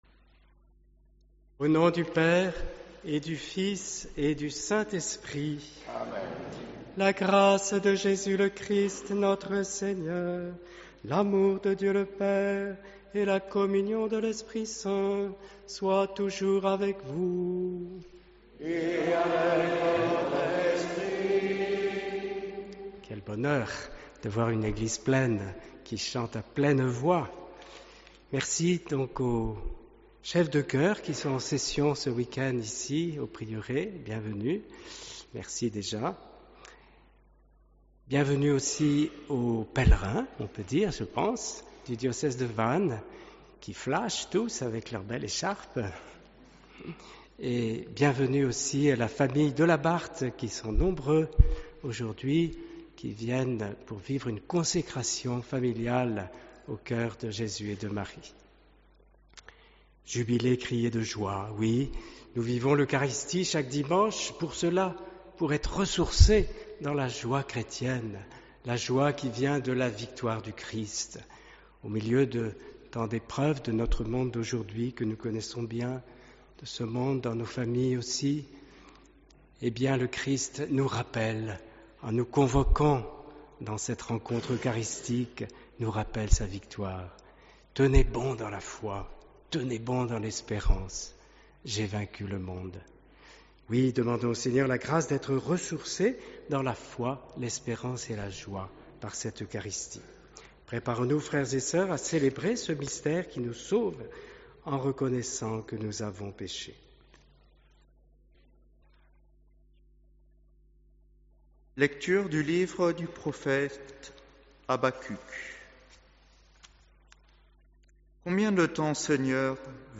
Quel bonheur de voir une église pleine qui chante à pleine voix.
Homélie J’oubliais de saluer les frères Diacres qui viennent aussi avec les pèlerins du diocèse de Vannes.